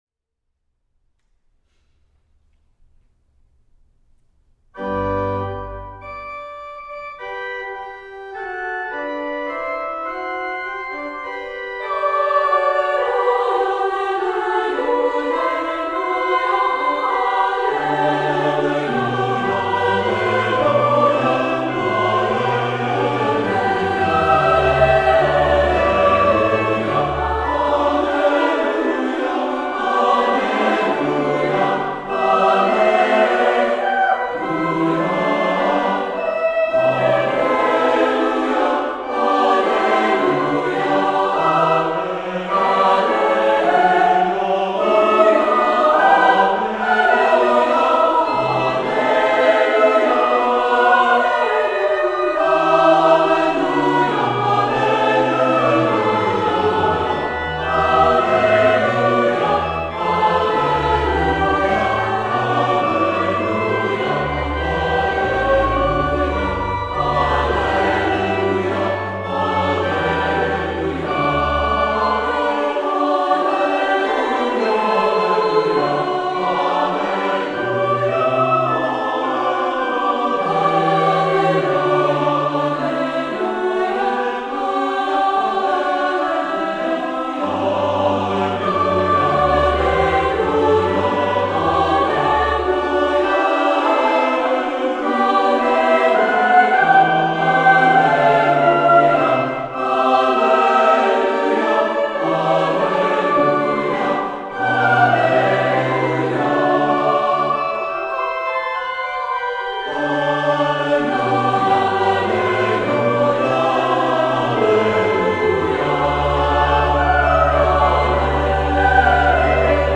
Voicing: SAATB